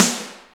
45.06 SNR.wav